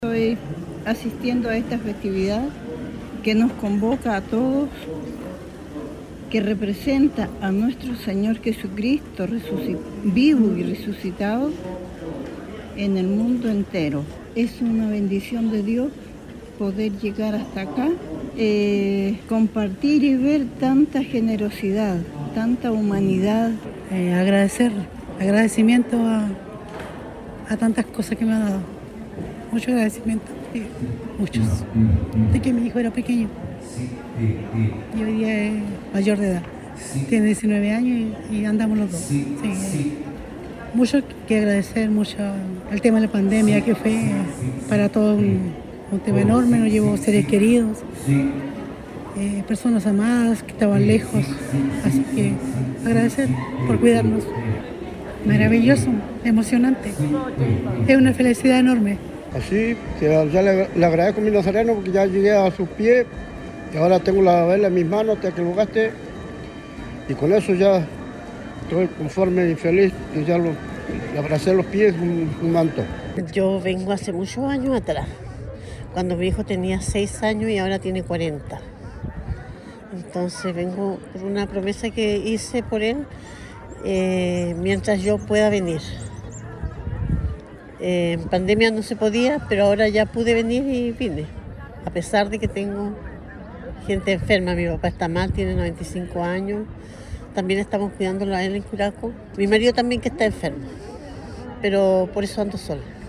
16-FELIGRESES-CAGUACH.mp3